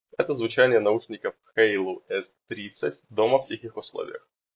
Микрофон:
Встроенный срдений, особенно в шумных условиях.
В тихих условиях: